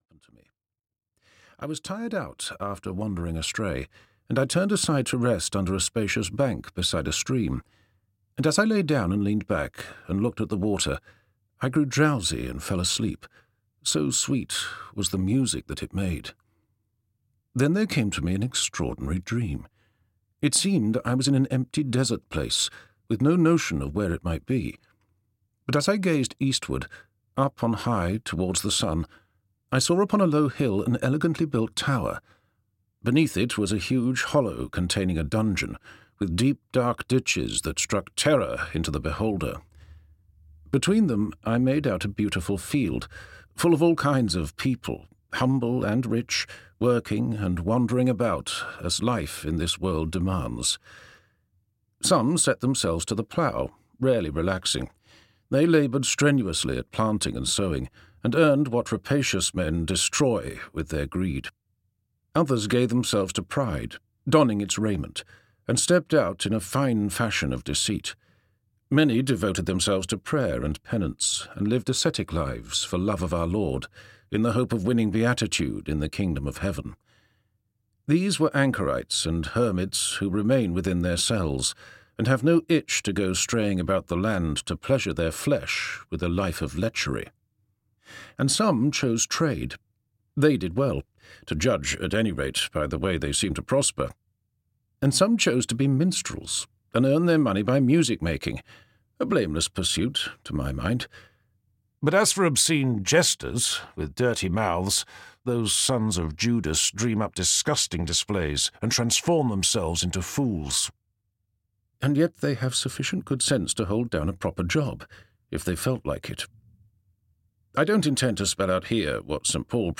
Piers Plowman (EN) audiokniha
Ukázka z knihy